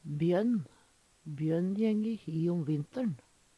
Bjørn som særnamn blir og uttala Bjønn